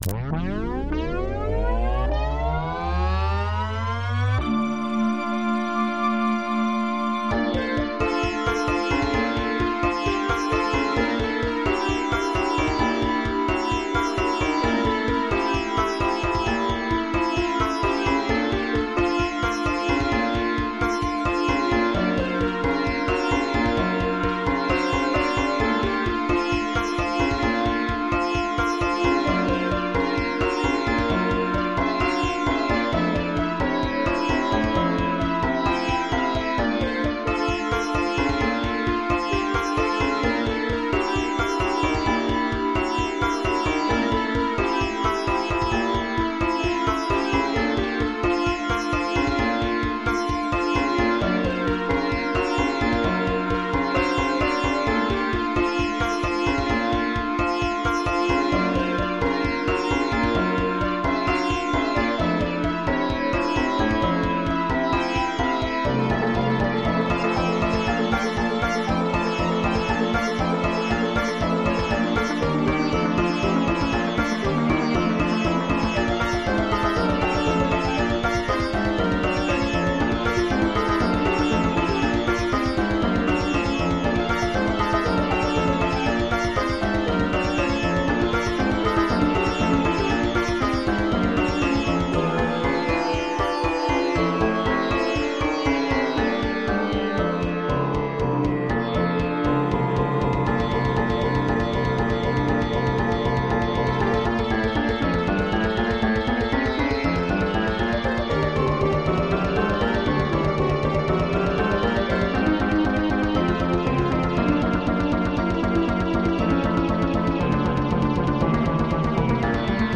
MIDI 57.4 KB MP3